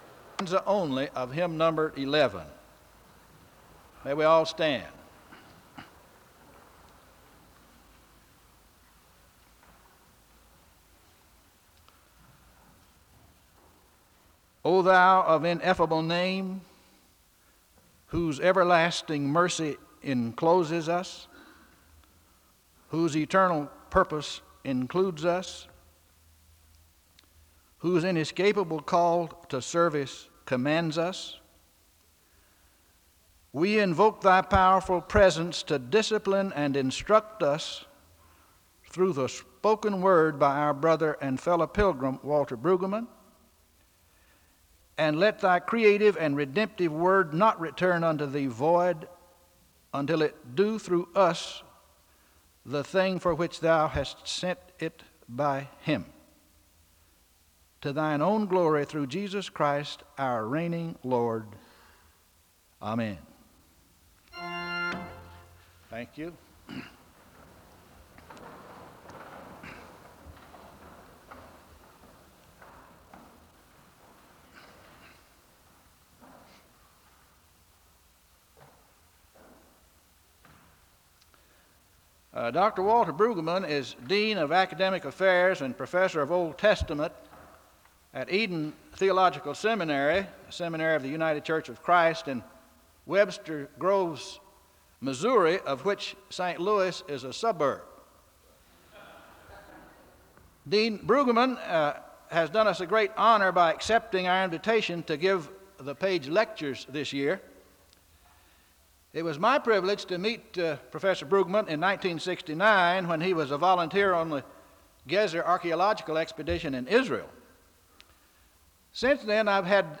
SEBTS Page Lecture - Walter Brueggemann October 7, 1981
SEBTS Chapel and Special Event Recordings